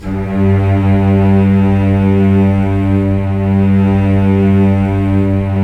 Index of /90_sSampleCDs/Roland LCDP13 String Sections/STR_Symphonic/STR_Symph. %wh